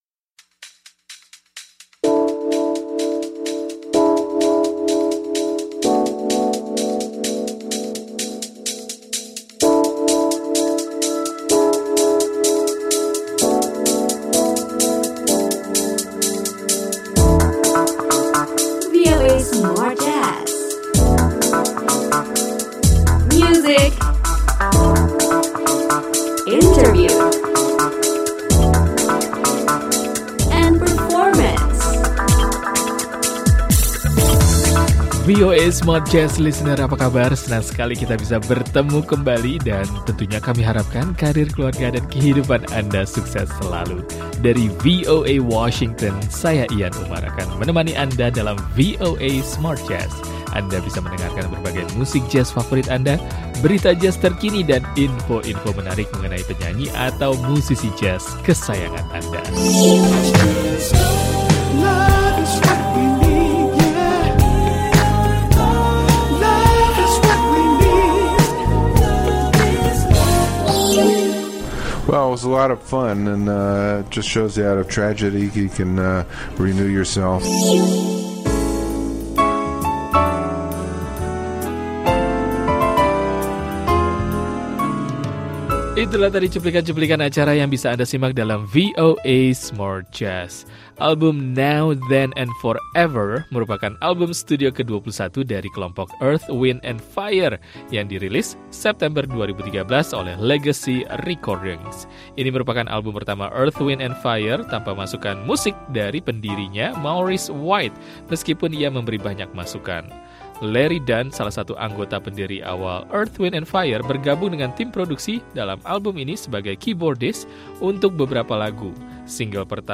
Pianis jazz